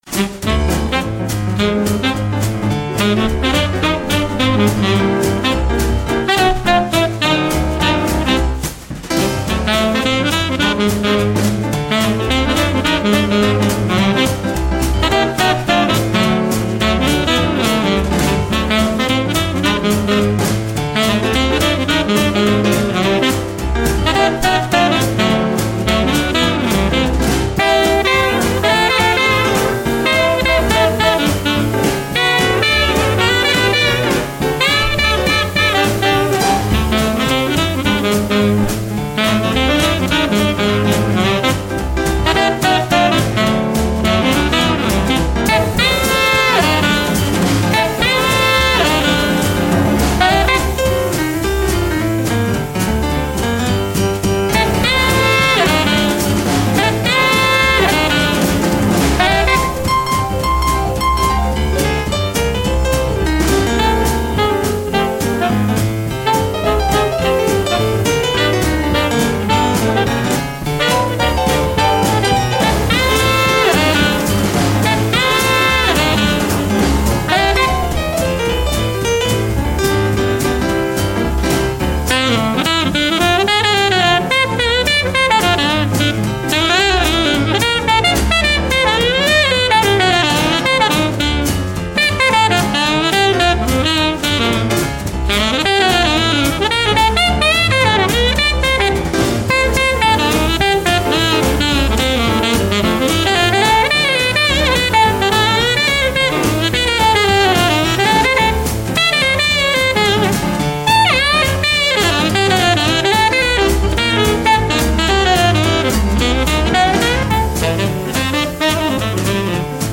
saxophone alto, clarinette,chant
saxophone ténor.
piano
batterie